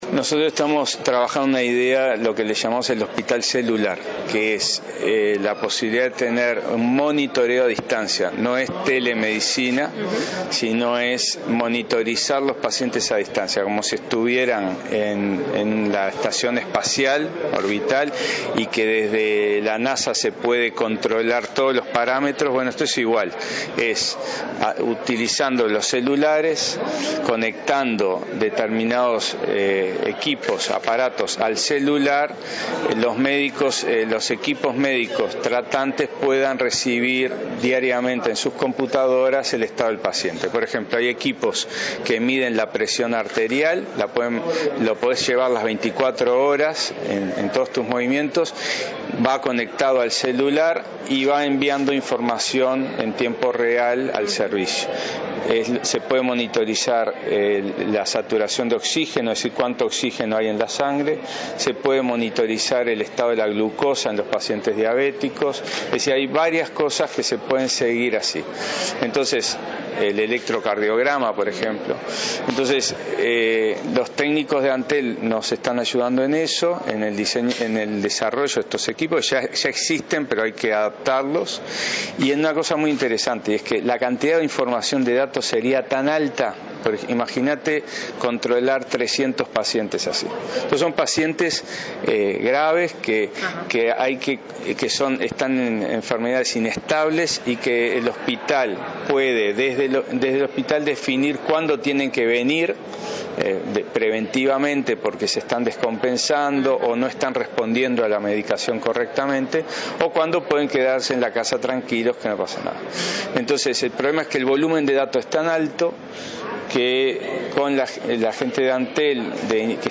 en la firma de un convenio con Antel.